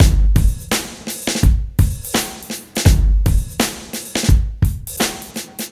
• 84 Bpm Drum Loop G Key.wav
Free drum loop sample - kick tuned to the G note.
84-bpm-drum-loop-g-key-nyR.wav